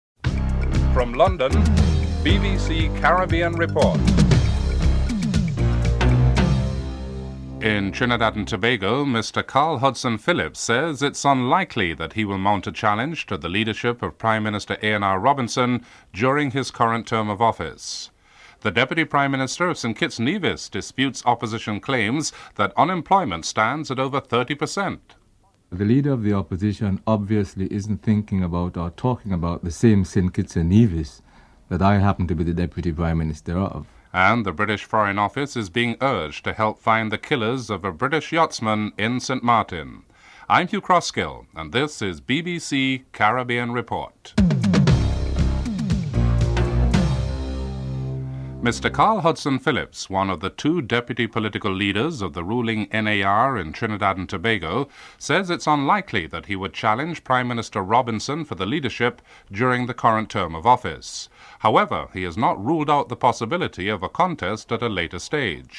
1. Headlines (00:00-00:48)